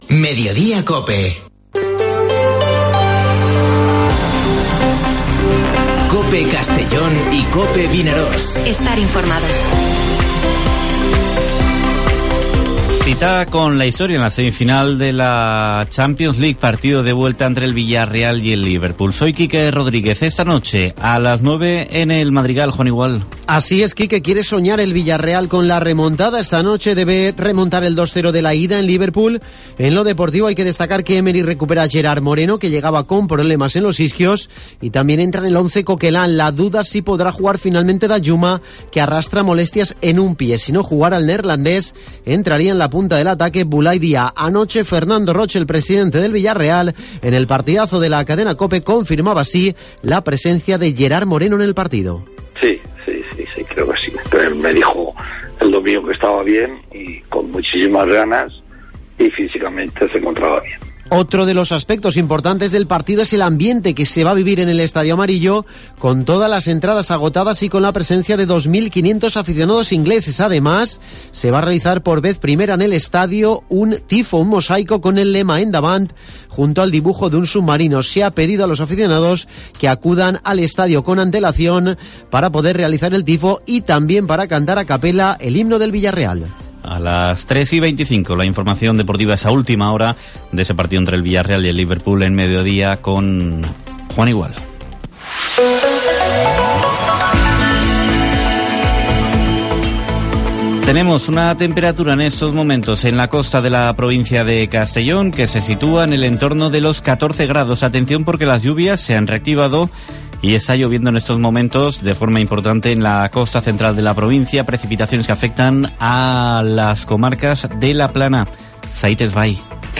Informativo Mediodía COPE en la provincia de Castellón (03/05/2022)